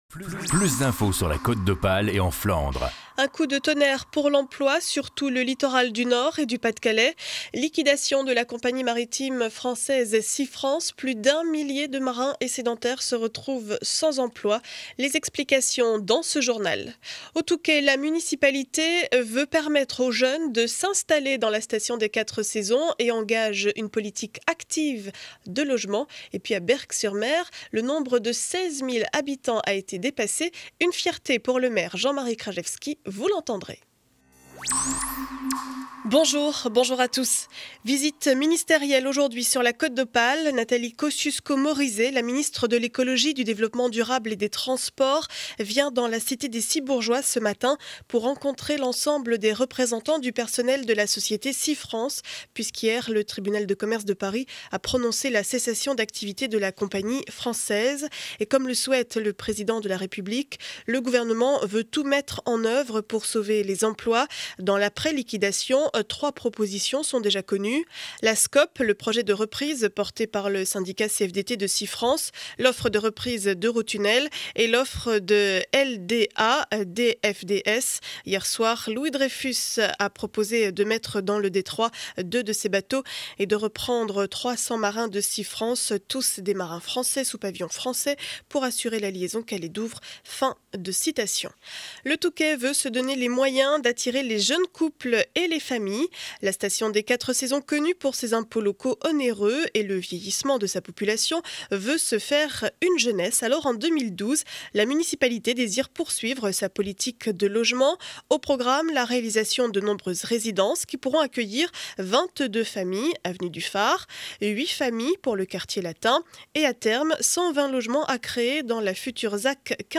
Journal du mardi 10 janvier 7 heures 30 édition du Montreuillois